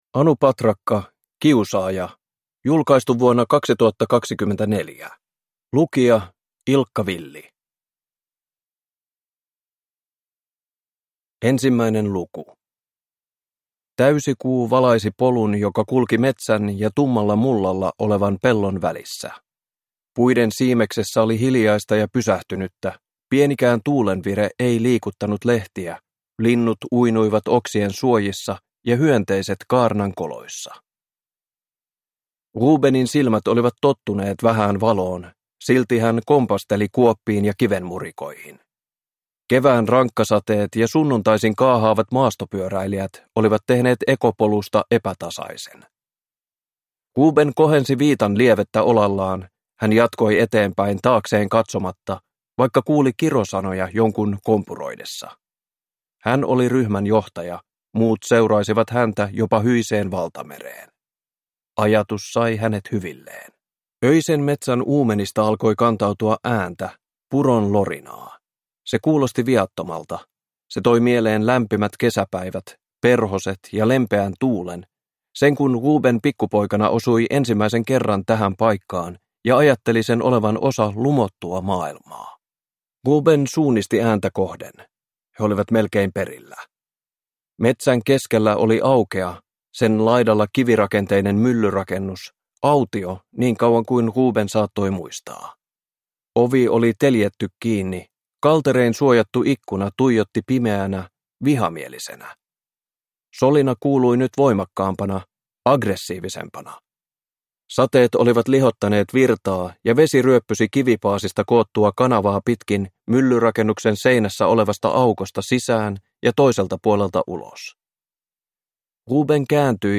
Kiusaaja (ljudbok) av Anu Patrakka